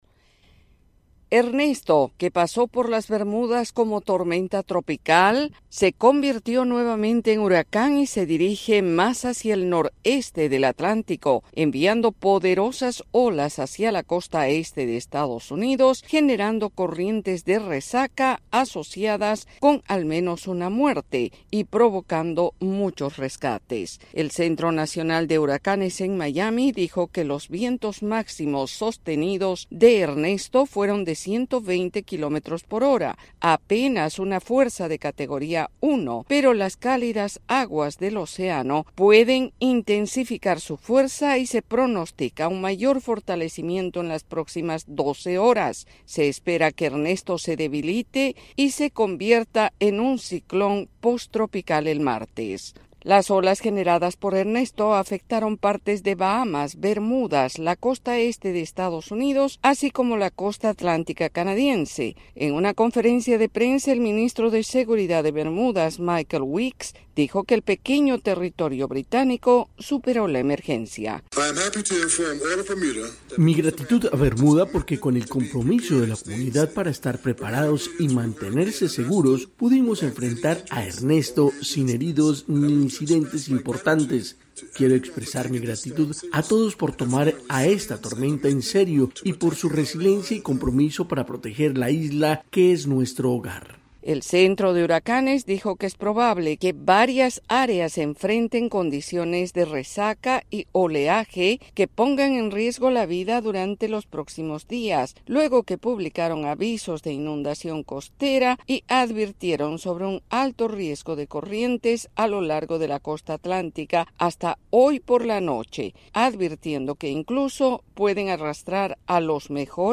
reporte